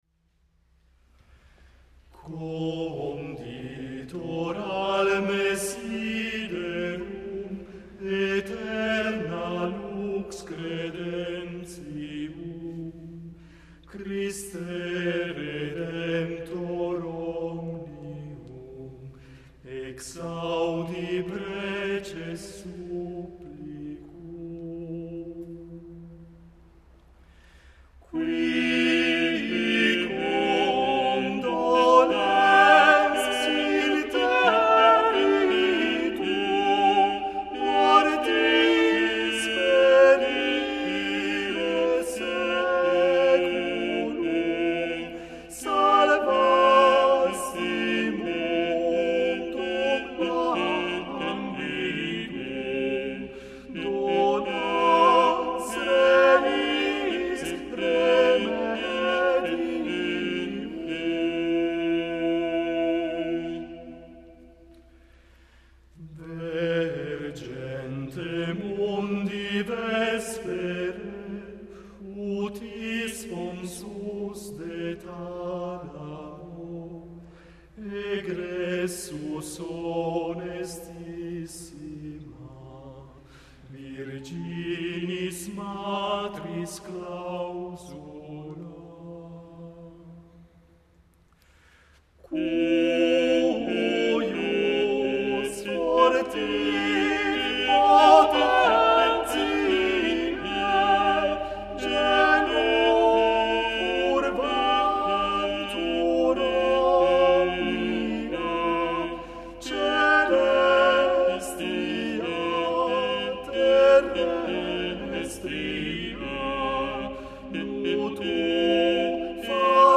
Guillaume Du Fay, inno Conditor alme siderum (